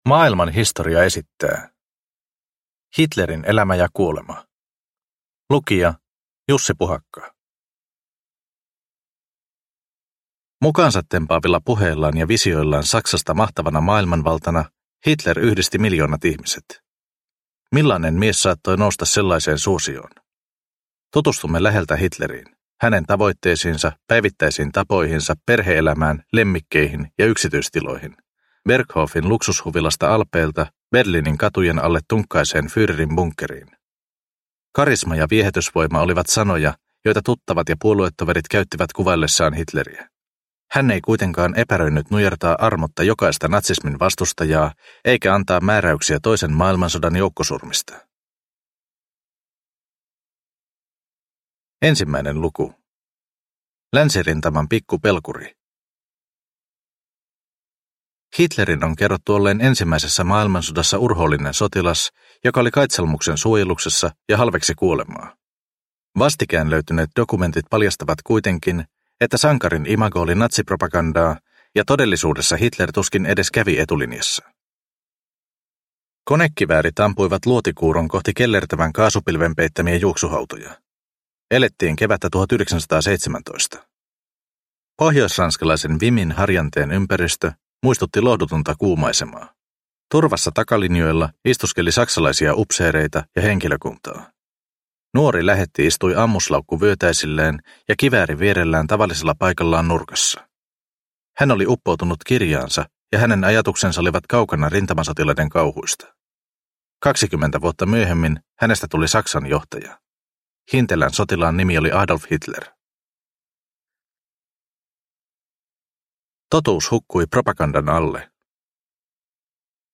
Hitlerin elämä ja kuolema (ljudbok) av Maailman Historia